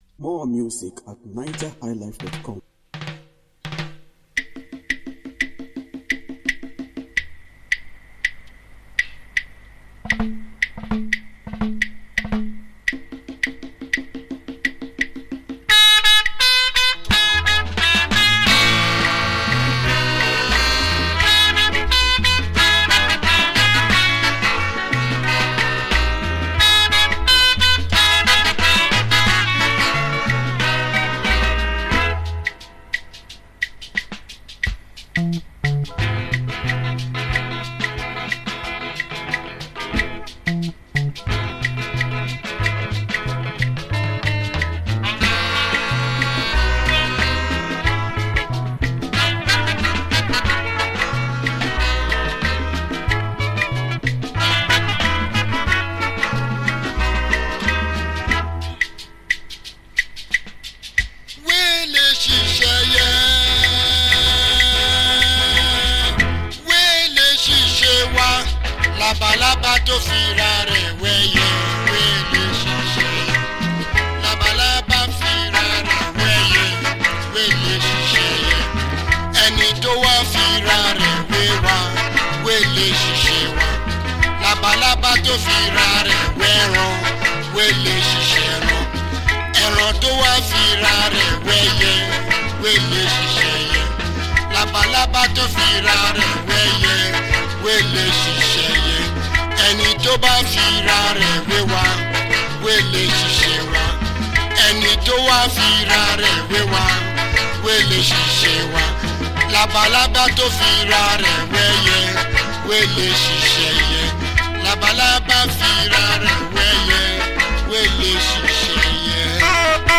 was a Nigerian trumpeter who played in the highlife style.